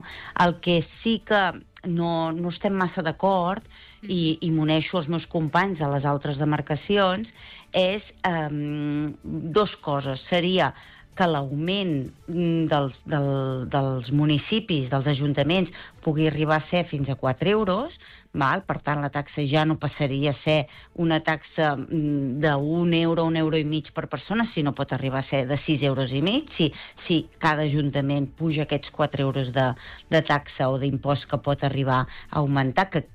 EntrevistesProgramesSupermatí